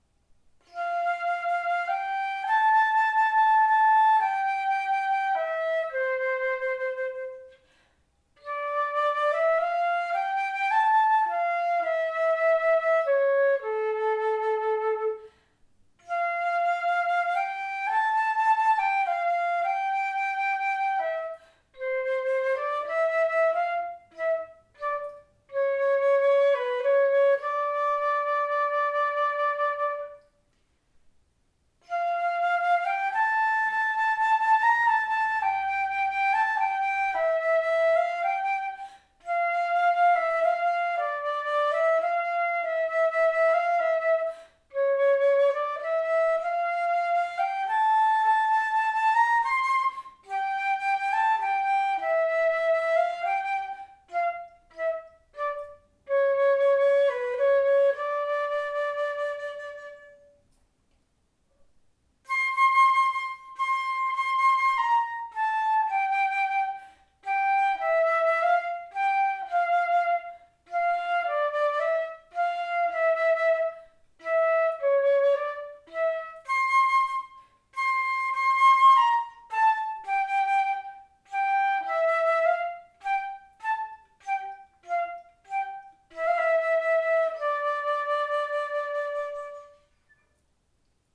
The Louis Lot flute is a silver instrument with a gold lip plate, open-hole keys, and a B foot.
As such, all the below recordings were made using the same recorder settings and player positioning (including microphone distance) within the same room.
Lyrical:  Louis Lot flute (c. 1906)
greensleeves_lyrical_lot.mp3